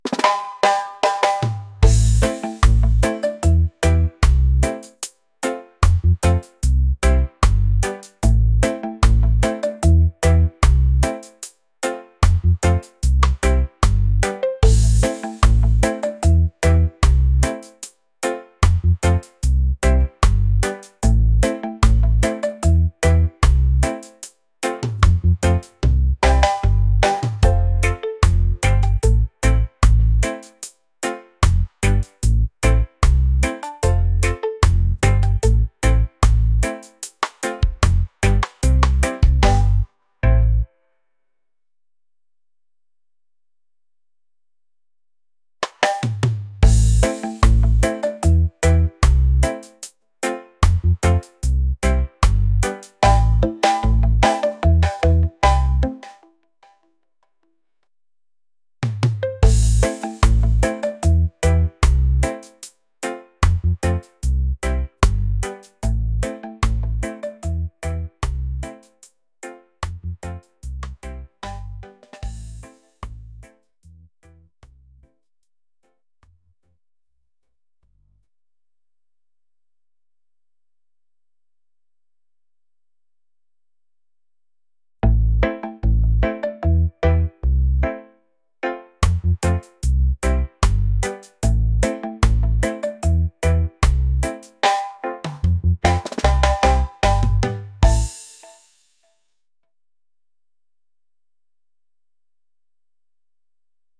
reggae | island | vibes | laid-back